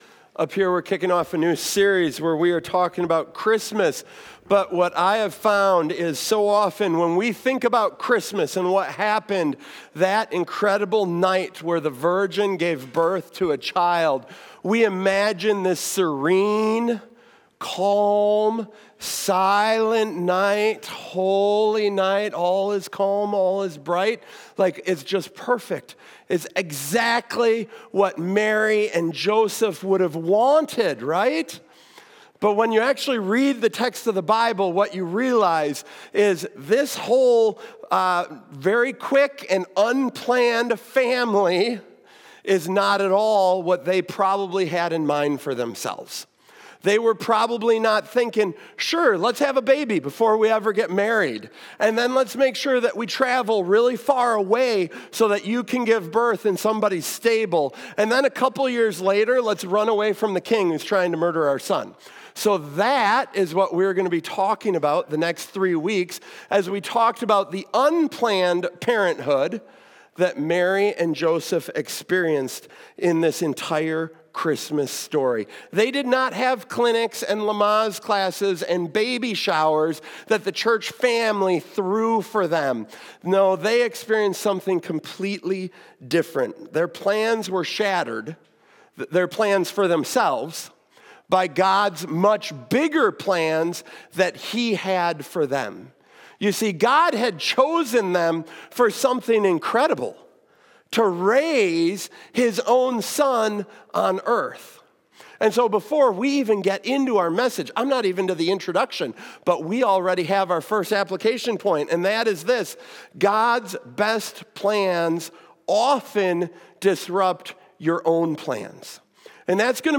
In this message, we’ll explore how God’s best plans often shatter our own, why the virgin birth matters more than you think, and what it means that God chose to enter our world through the most unplanned, chaotic moment imaginable.